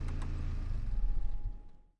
发动机启动的内饰 宝马118i
描述：BMW 118i的发动机启动：软件中有一个东音，并且有一个运行时间。
标签： 内饰 宝马 汽车 OWI 低吼 发动机
声道立体声